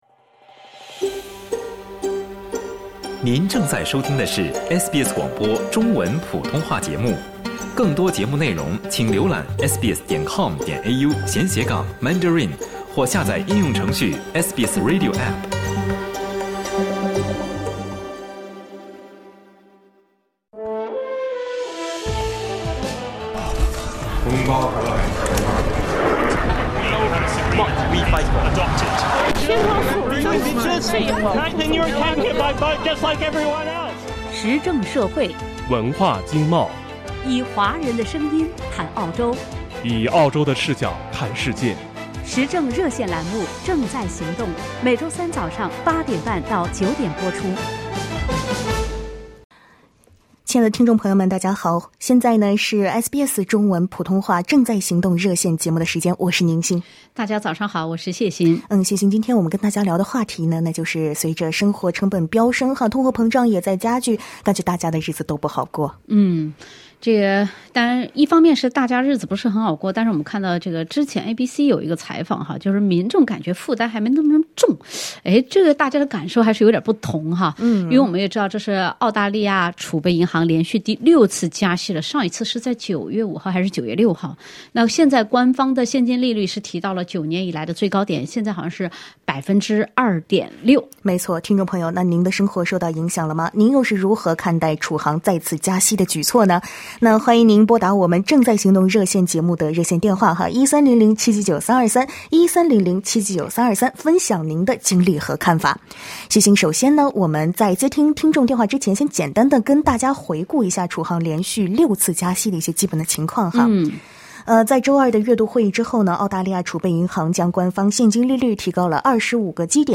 在节目中，听友们还就加息的原因、资本主义国家经济规律、现在与过去的对比等话题发表了看法。